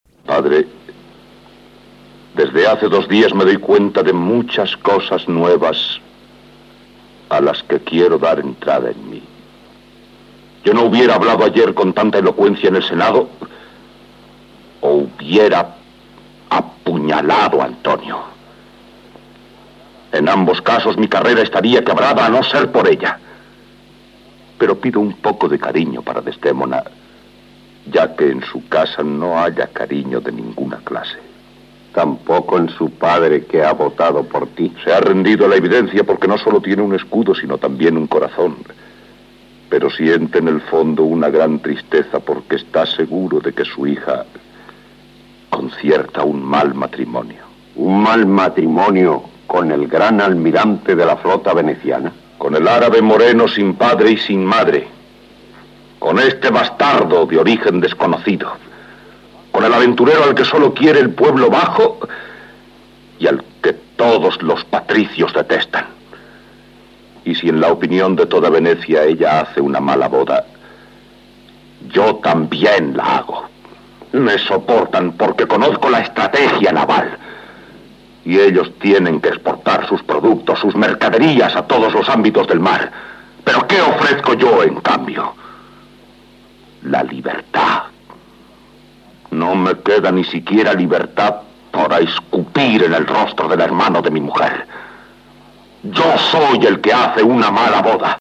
Fragment de l'adaptació radiofònica d'"Otelo: el moro de Venecia" de William Shakespeare feta pel Grupo de Arte Radiofónico de EAJ15
Ficció
L'enregistrament, per tant, estava fet a la dècada de 1950.